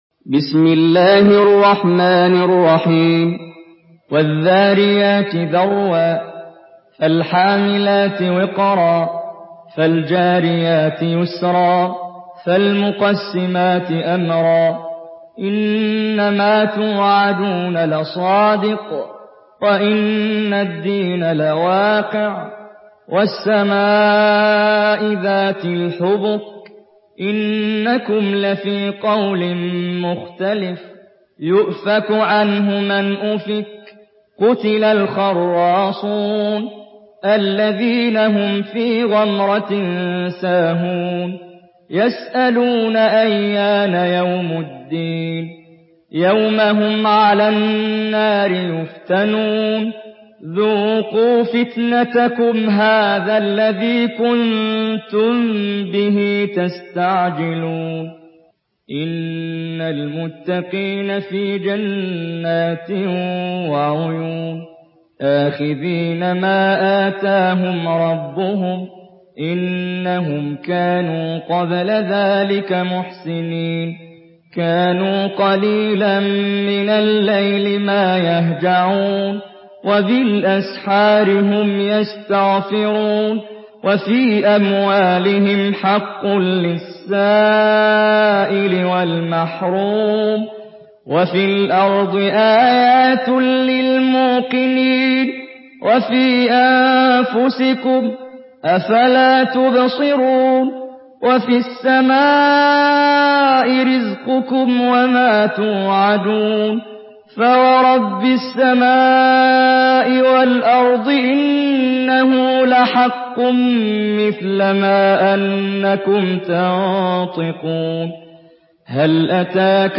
Surah Ad-Dariyat MP3 by Muhammad Jibreel in Hafs An Asim narration.
Murattal Hafs An Asim